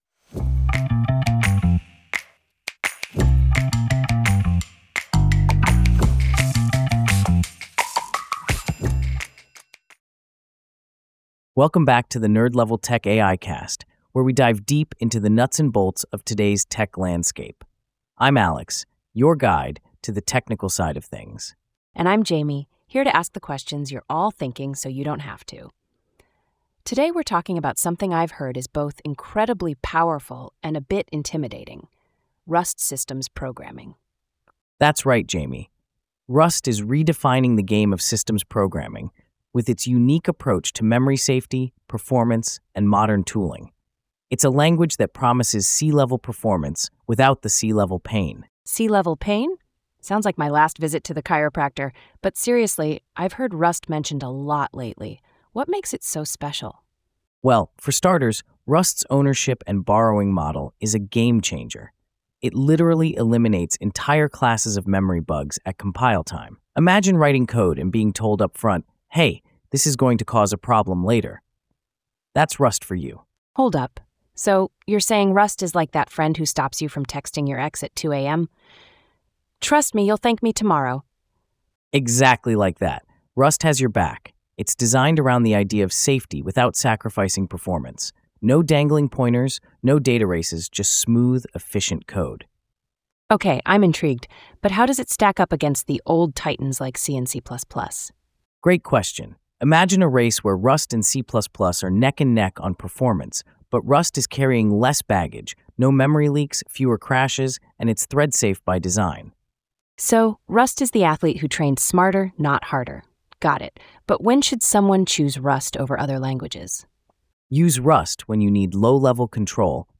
Listen to the AI-generated discussion